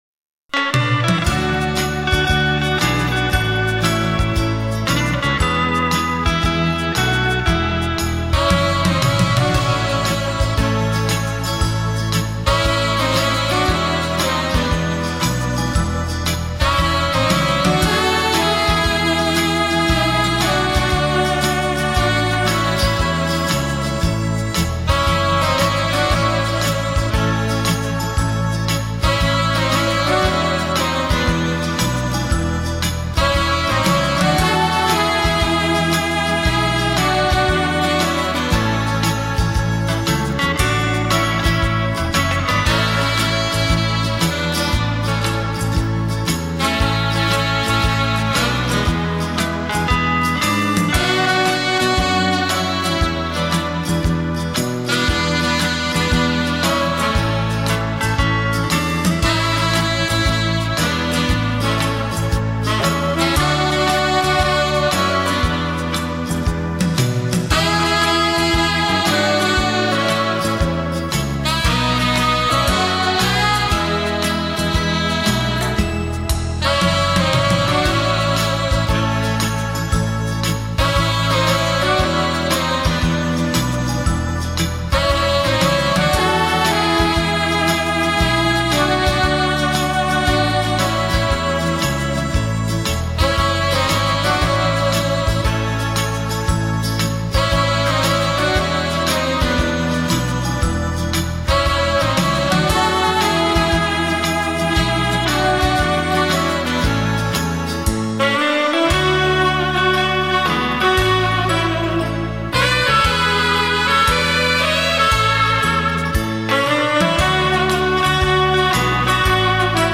这是一组双萨克斯演奏的曲目 节奏明快 富有朝气 喜欢纯音乐的朋友 不要错过！